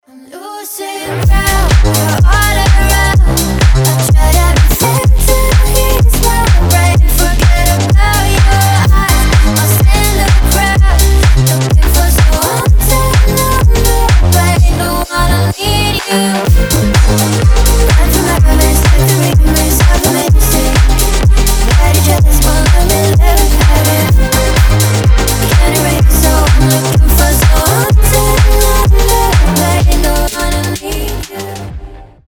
slap house